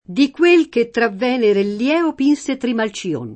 Trimalcione [trimal©1ne] (raro Trimalchione [trimalkL1ne]) pers. m. stor. — dier. poet.: di quel che tra Venere e Lieo Pinse Trimalcïon [